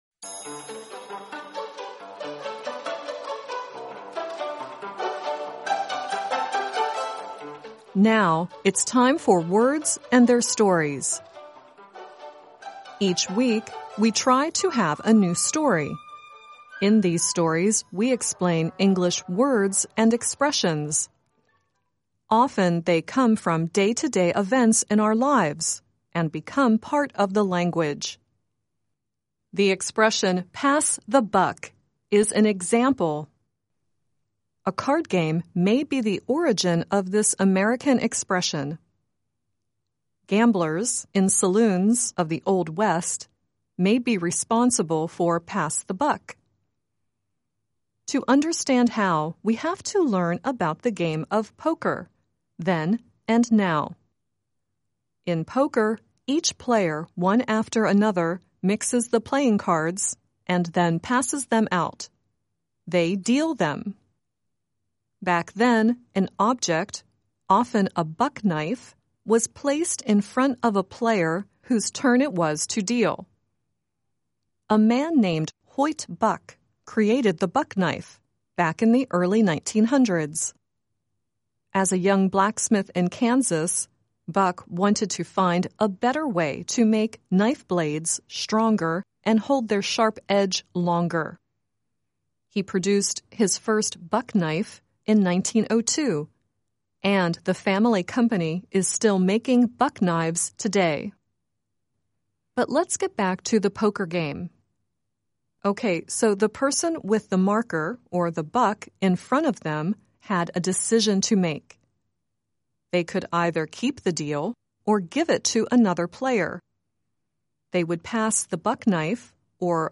The song at the end is Kenny Rogers singing "The Gambler."